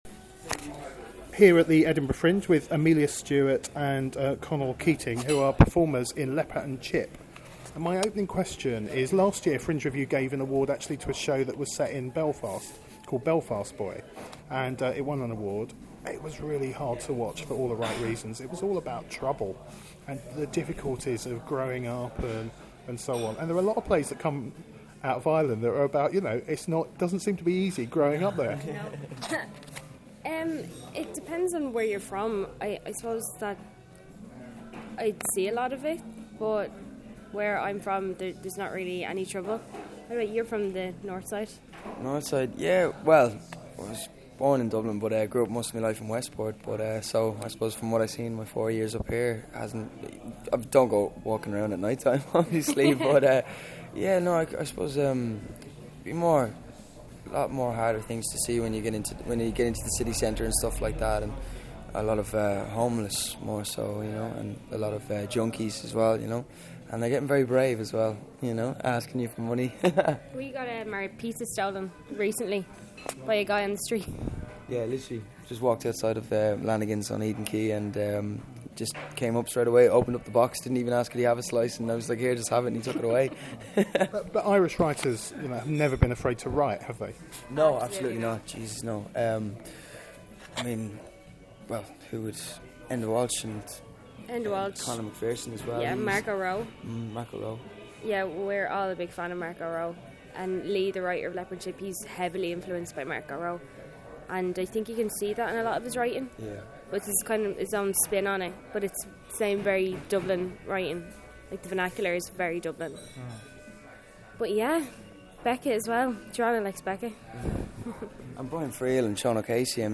How do these performers deliver both visceral drama and comedy inherent in the work? Listen to our interview